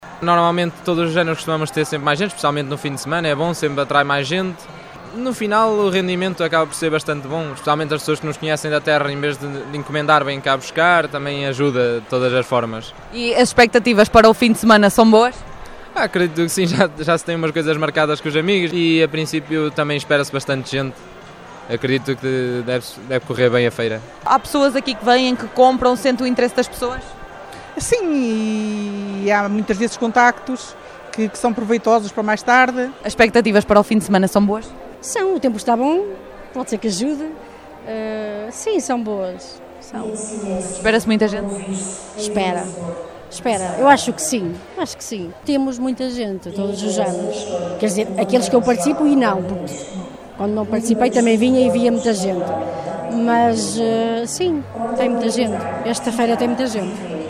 Os expositores também se mostram positivos quanto ao fim de semana e esperam mesmo muitas vendas e uma enchente.